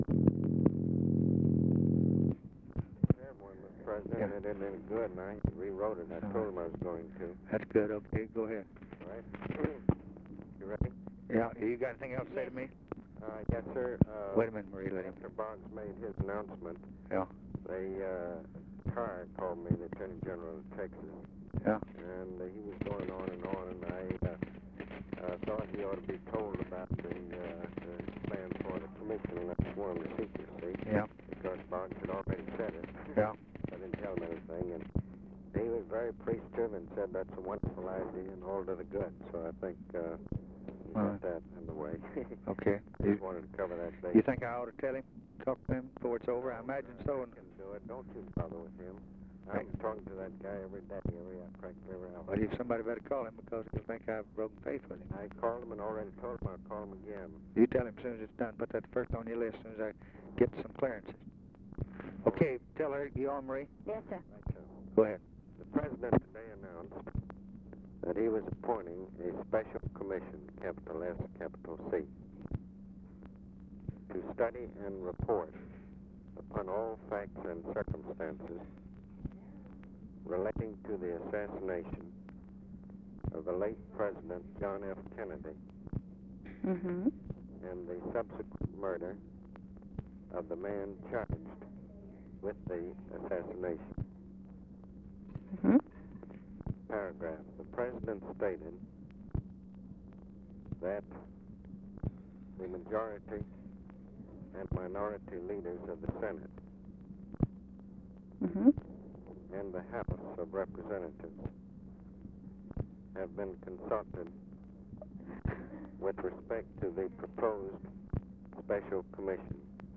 Conversation with ABE FORTAS, November 29, 1963
Secret White House Tapes